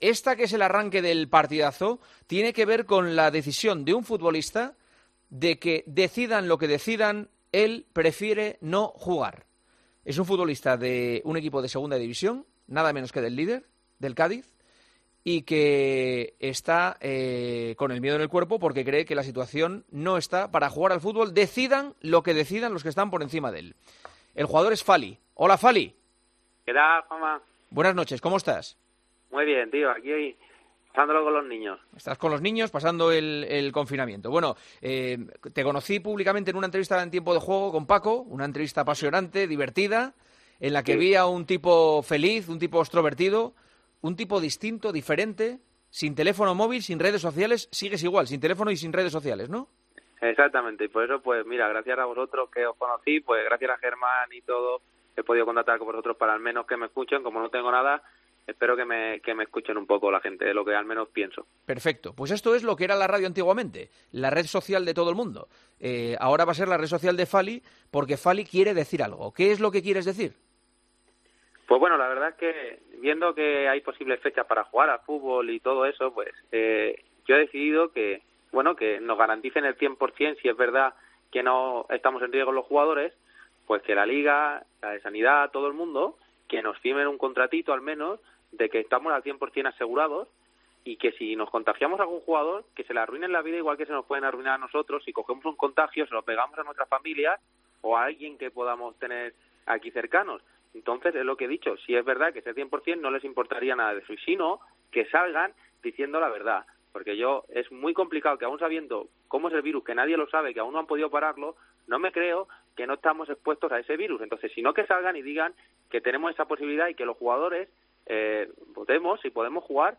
Sin pelos en la lengua y hablando sin tapujos, el central del Cádiz CF Fali ha dado su punto de vista sobre la posible vuelta del fútbol dejando claro que si hay un mínimo riesgo de contagio por coronavirus “dejaré el fútbol”.
El programa nocturno de Juanma Castaño abría con una entrevista al jugador cadista que quería, por decisión propia, dejar clara su opinión sobre todo lo que se está hablando en los últimos días.